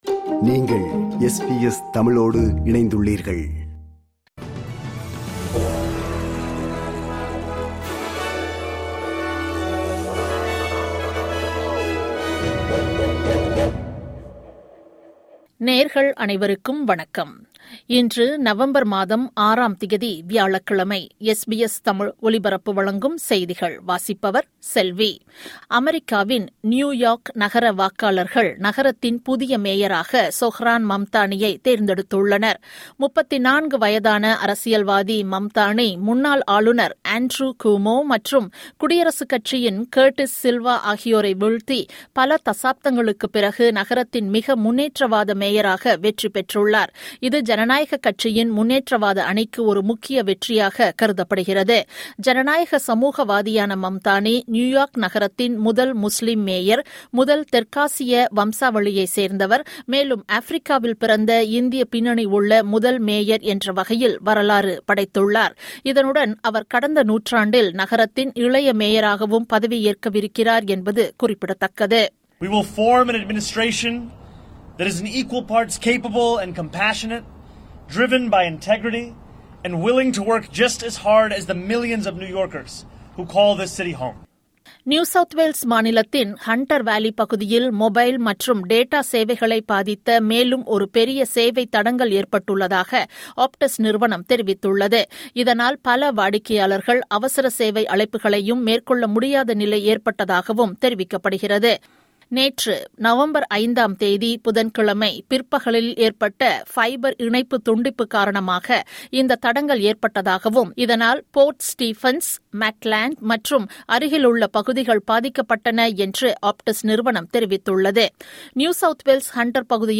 இன்றைய செய்திகள்: 06 நவம்பர் 2025 வியாழக்கிழமை
SBS தமிழ் ஒலிபரப்பின் இன்றைய (வியாழக்கிழமை 06/11/2025) செய்திகள்.